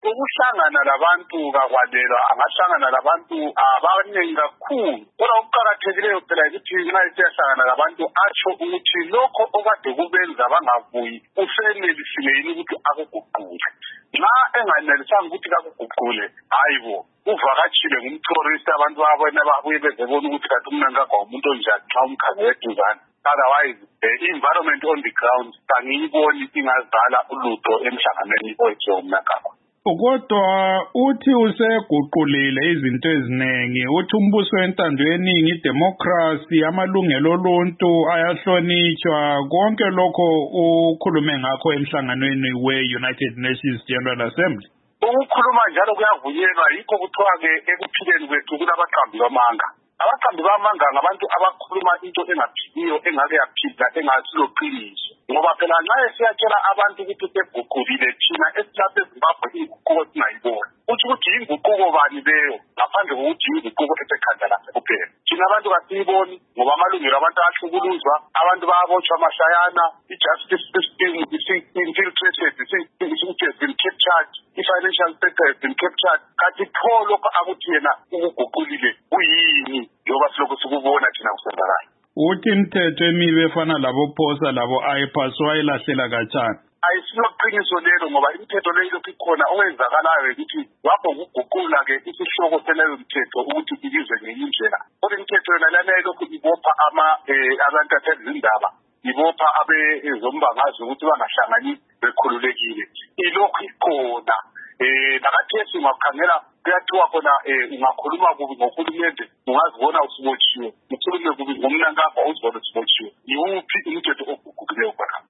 Ingxoxo loMnu Abednico Bhebhe